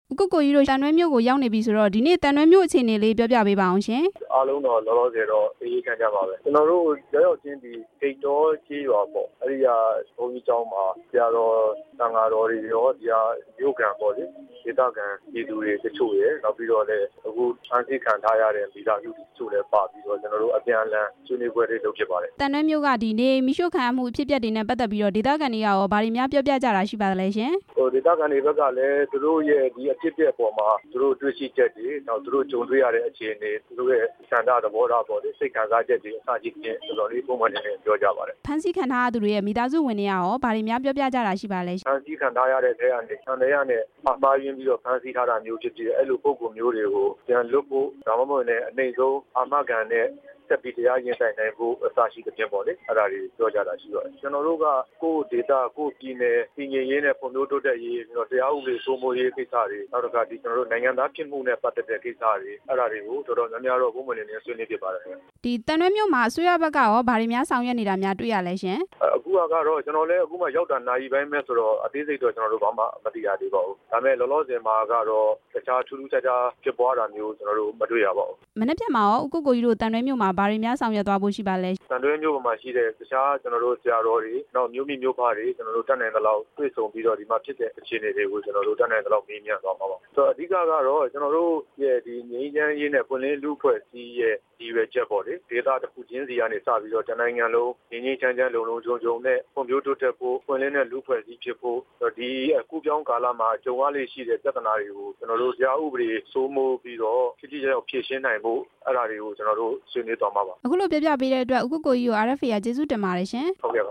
ဦးကိုကိုကြီးကို ဆက်သွယ်မေးမြန်းချက်